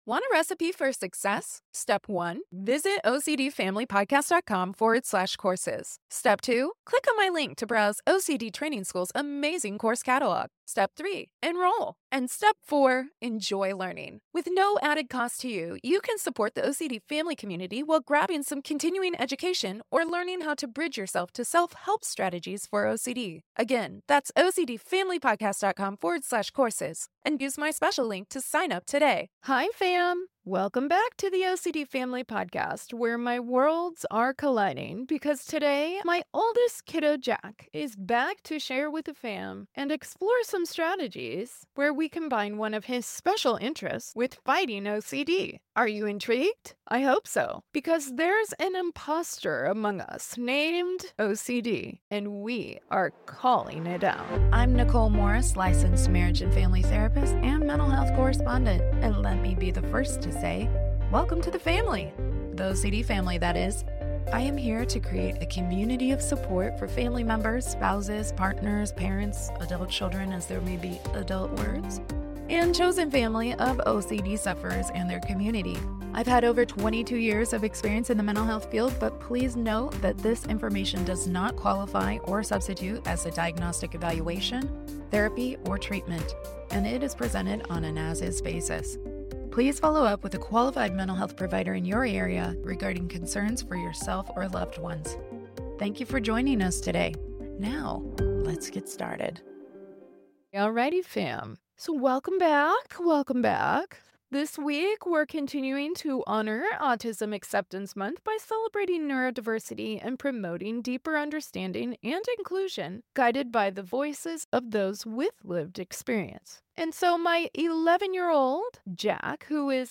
Together, we discuss bridging cognitive and psychodynamic approaches in treatment, blending science with artistry and cultivating self compassion to honor the heart of those with lived experience. So join the conversation, as we explore the nuance and appreciate the gifts within our community’s struggles.